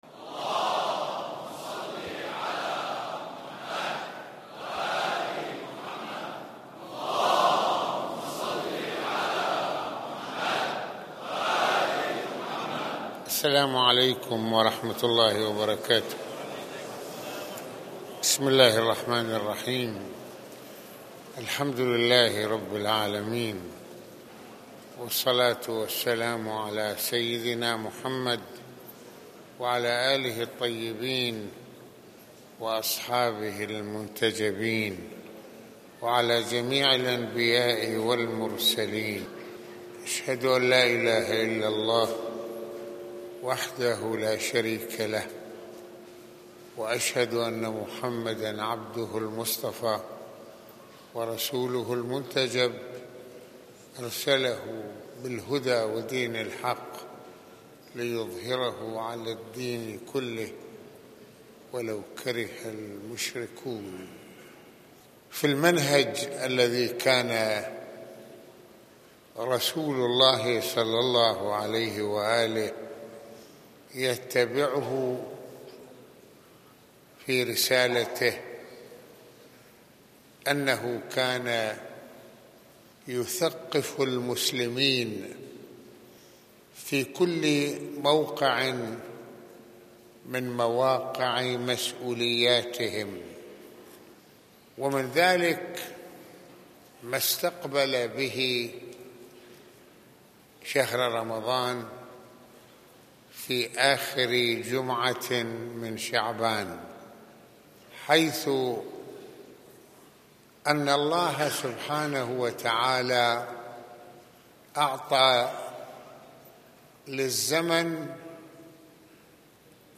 المنهج الروحي للمؤمن في هذا الشهر | محاضرات رمضانية